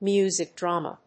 アクセントmúsic dràma